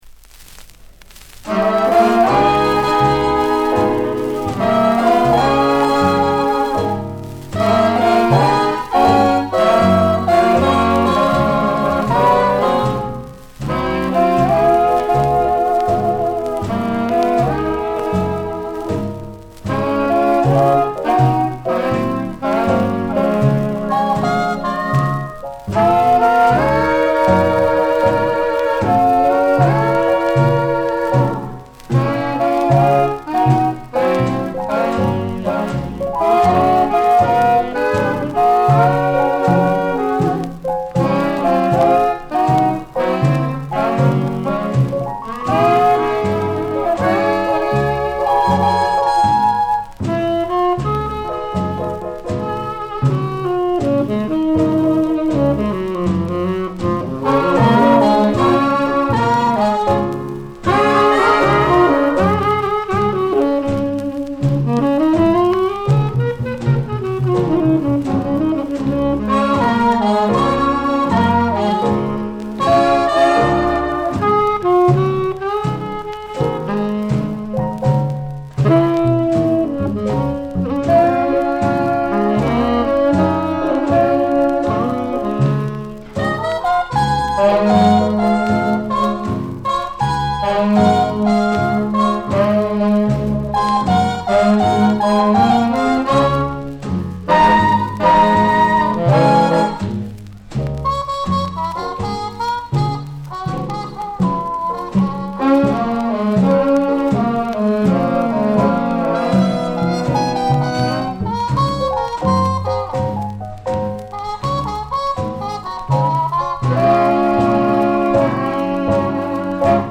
78 rpm
mono
tenor sax & leader
trumpet